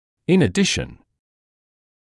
[ɪn ə’dɪʃn][ин э’дишн]в дополнение